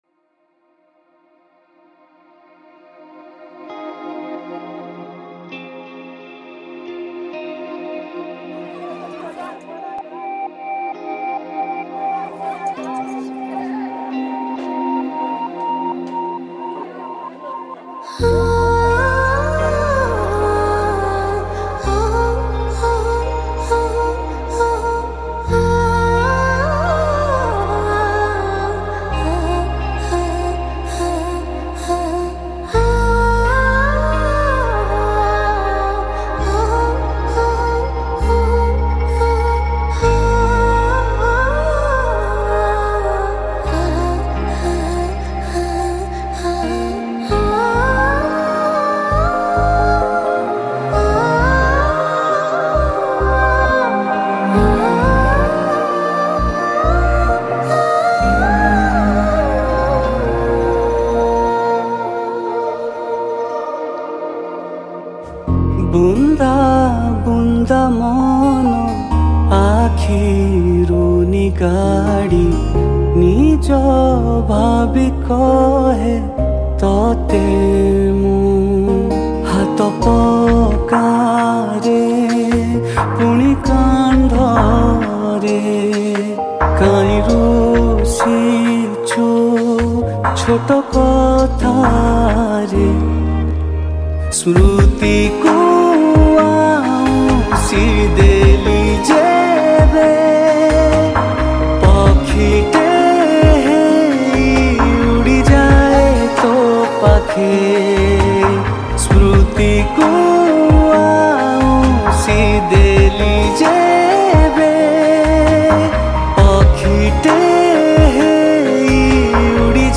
Keyboard Programming
Rhythm Programming